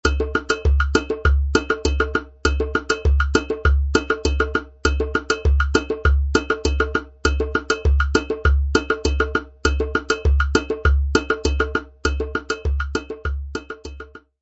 African Drum Beat home page Rhythm Notations (set1)
Bell, Clave, 3 sections.